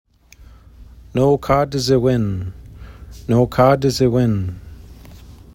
NOE-kah-dih-zih-WIN), un terme ojibwé qui signifie être dans un état de calme avec une douce émotion associée à l’empathie.